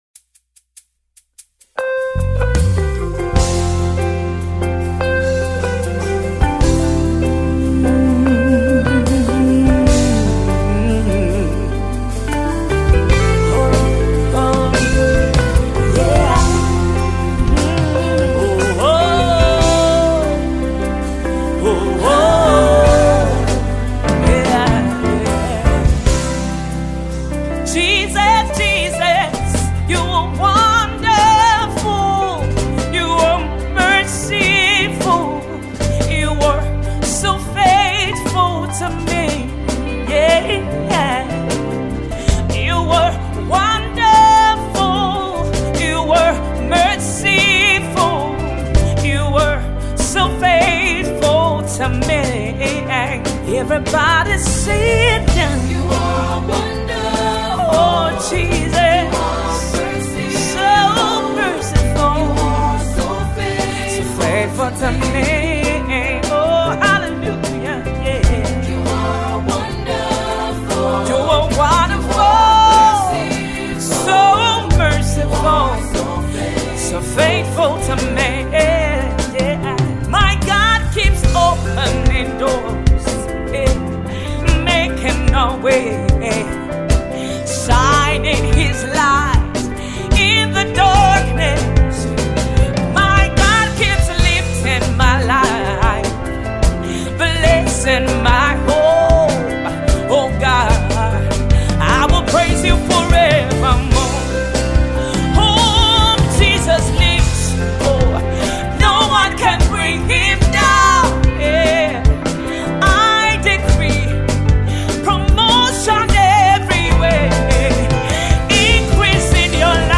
Award Winning Gospel Songstress